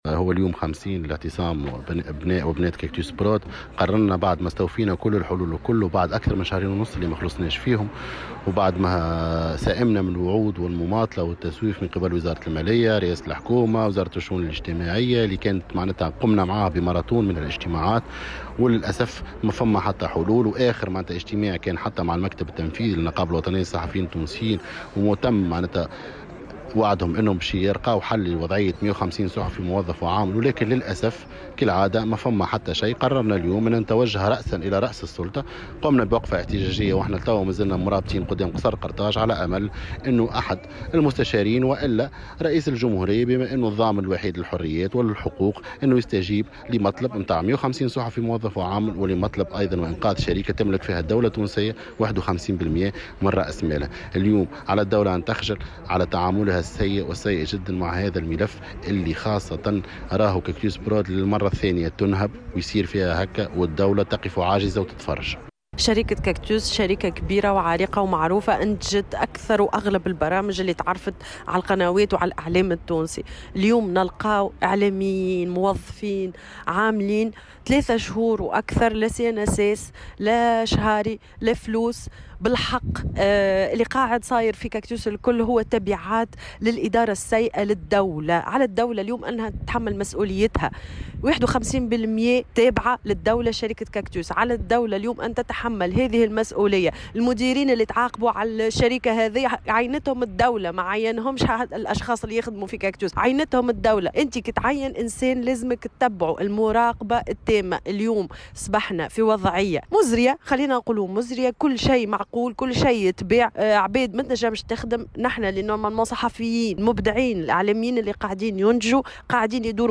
صحفيون في كاكتوس برود